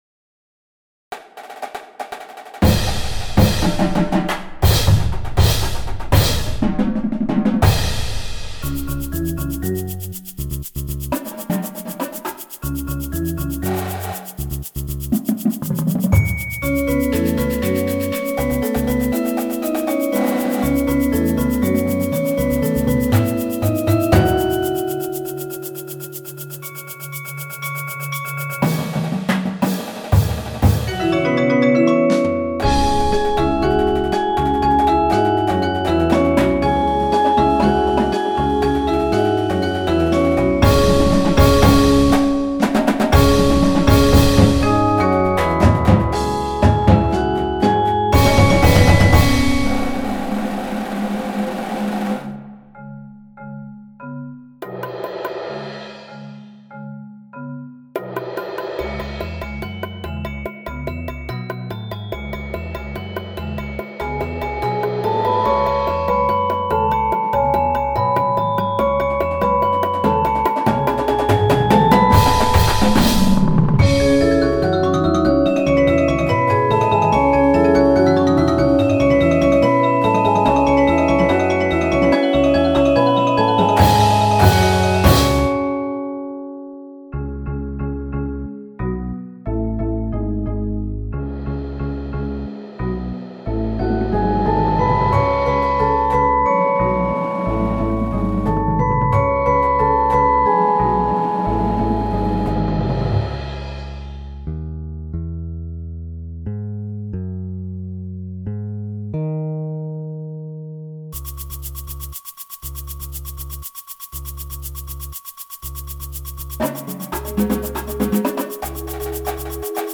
Funk. Funk. Funk.
Snares
Tenors
5 Bass Drums
Marching Cymbals
Marimba 1, 2
Vibraphone 1, 2
Xylophone
Glockenspiel
Bass Guitar
Auxiliary Percussion 1, 2, 3